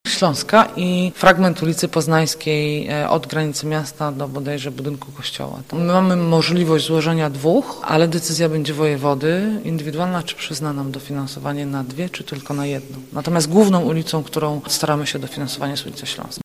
Mówi wiceprezydent Agnieszka Surmacz: